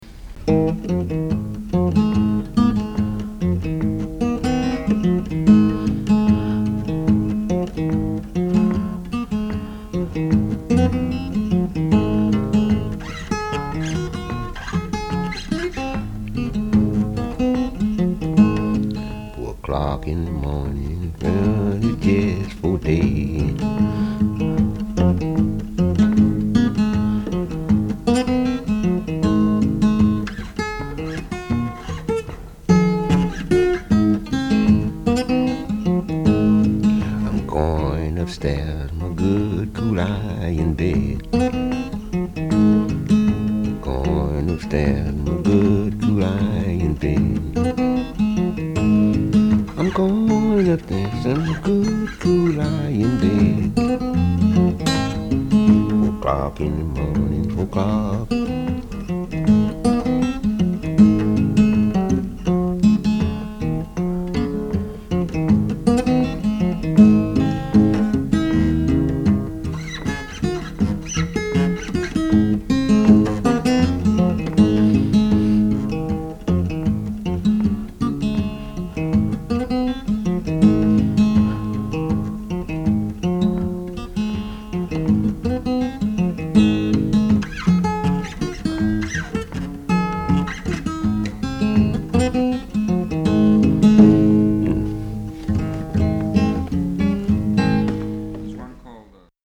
Genre : Rock, Blues, Folk